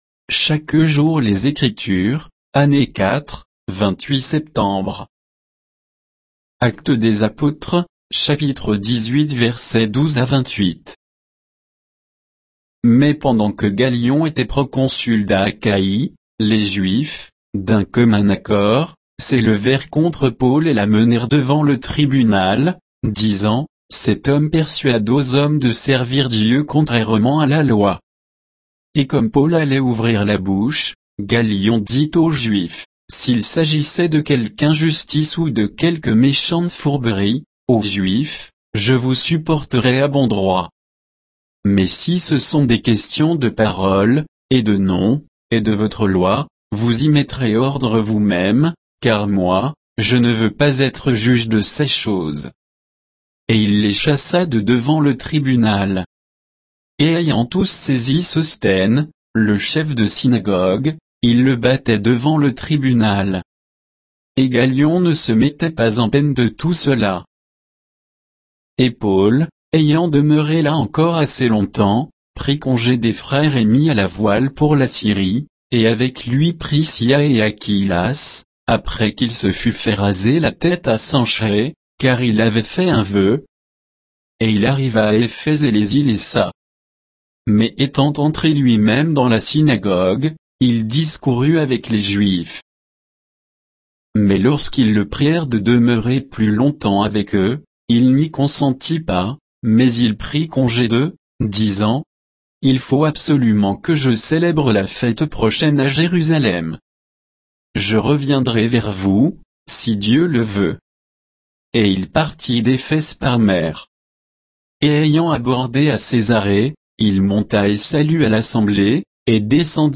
Méditation quoditienne de Chaque jour les Écritures sur Actes 18, 12 à 28